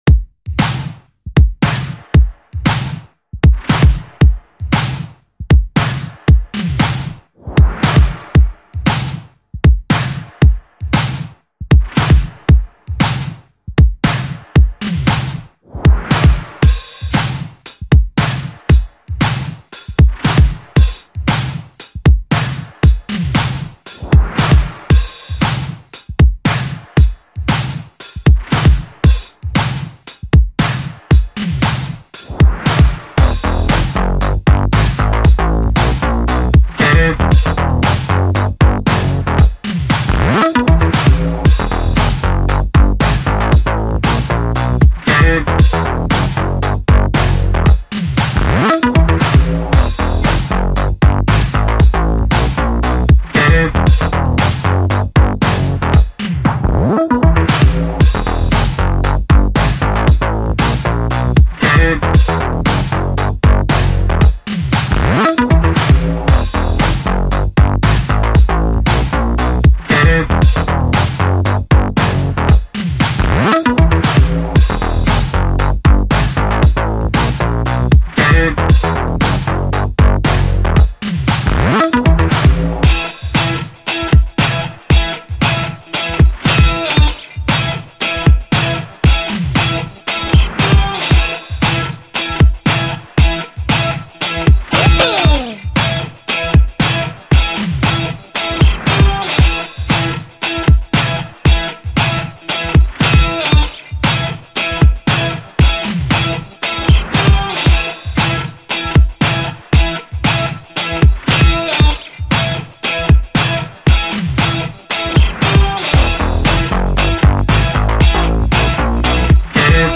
:) Anyways I'm here with a new funk track.